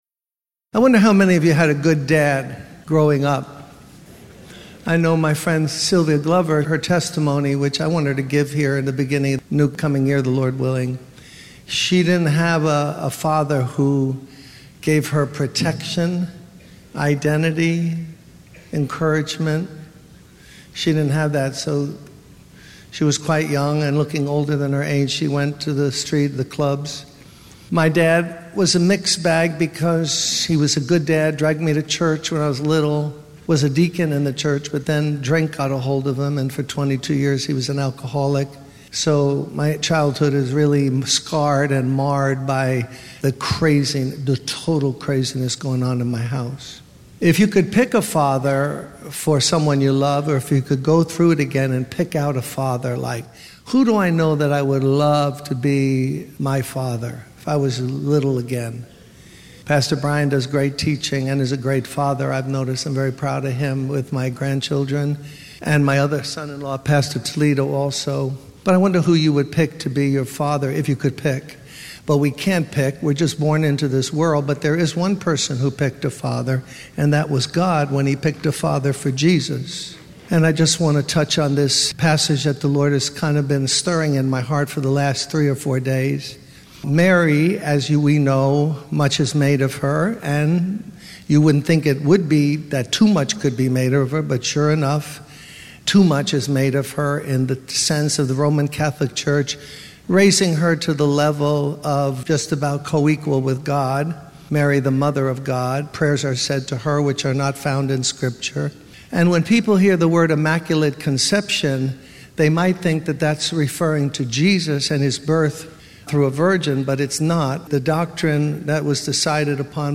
In this sermon, the speaker emphasizes the importance of holding on to what God has shown us, even in the face of adversity and doubt. He uses the example of Joseph, who believed in the miraculous conception of Mary and stood by her despite ridicule and mockery.
The sermon concludes with a prayer for safety and guidance in evangelism.